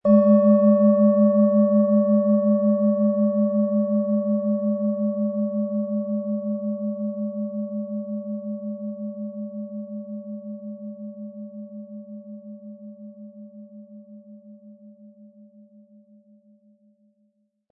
Nach Jahrhunderte alter Tradition von Hand getriebene Planetenklangschale Uranus.
Unter dem Artikel-Bild finden Sie den Original-Klang dieser Schale im Audio-Player - Jetzt reinhören.
Durch die überlieferte Herstellung hat diese Schale vielmehr diesen besonderen Ton und die innere Berührung der liebevollen Handfertigung.
Ein schöner Klöppel liegt gratis bei, er lässt die Klangschale harmonisch und angenehm ertönen.
PlanetentonUranus
MaterialBronze